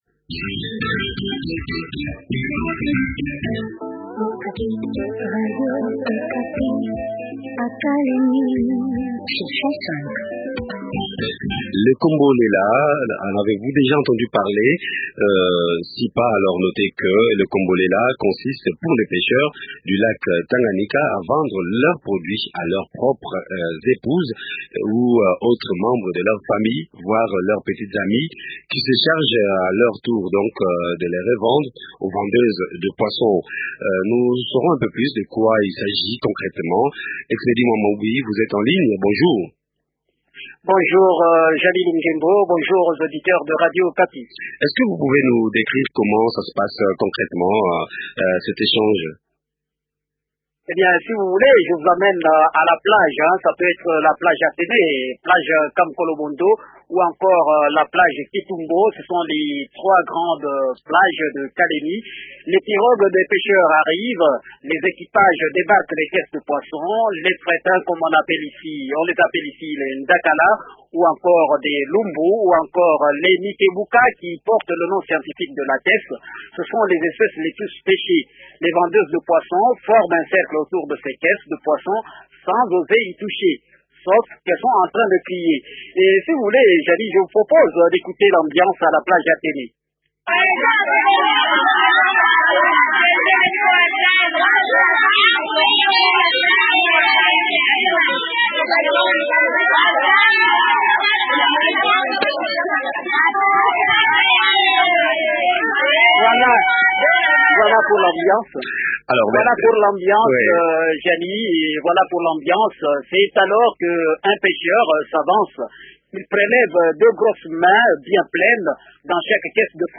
Jean Lumaliza, administrateur de territoire de Kalemie promet la reprise de la pêche semi-industrielle d’ici la fin de l’année.